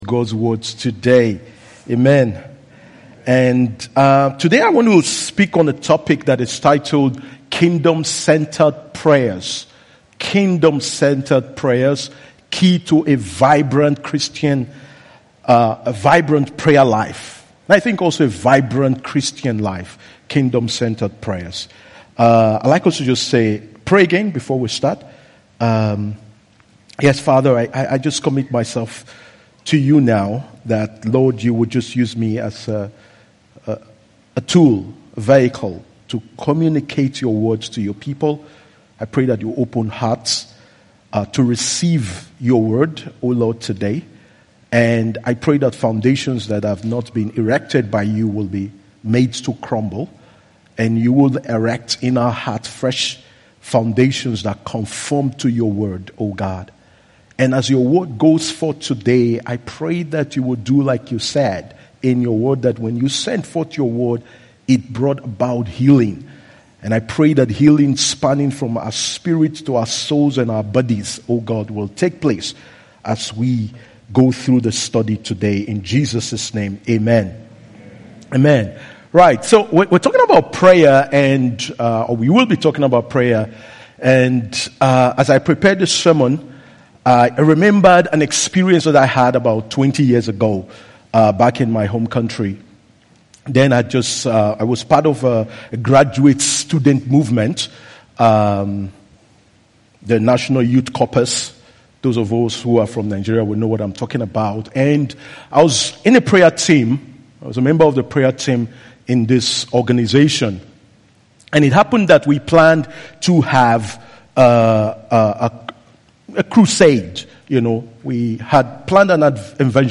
IBC Hamburg Predigt